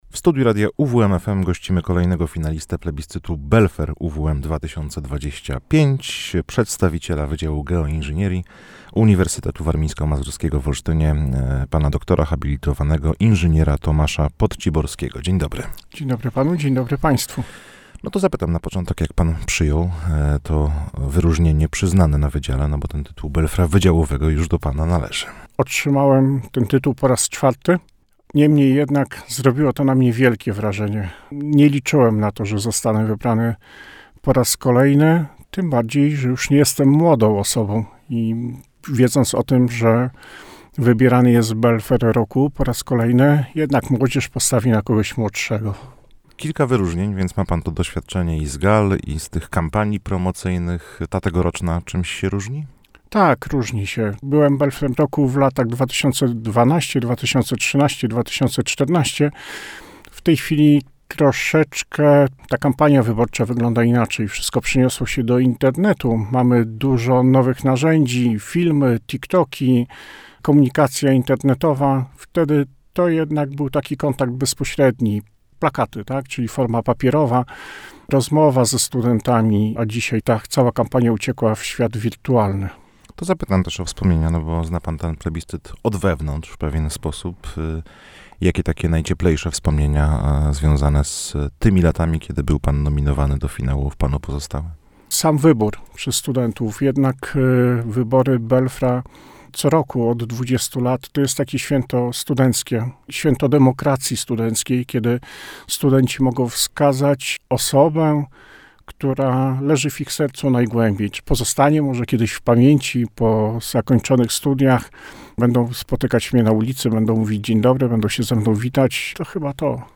– mówił w studiu Radia UWM FM.